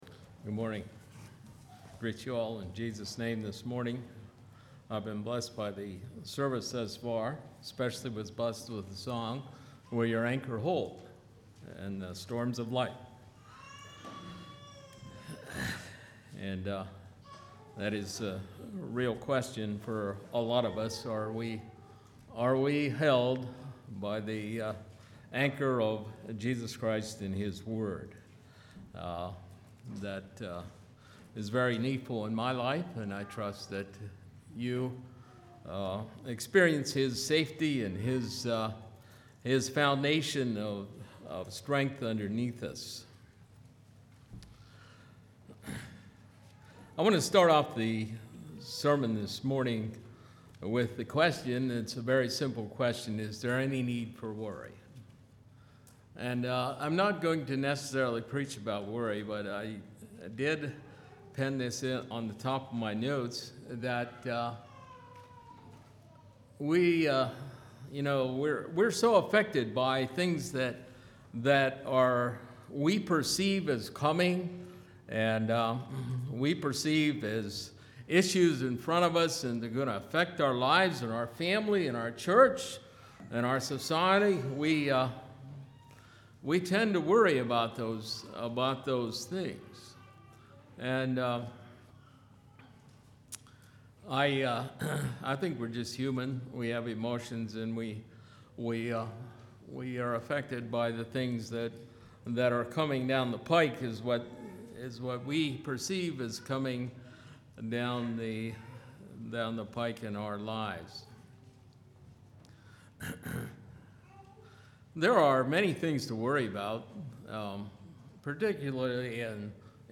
2017 Sermon ID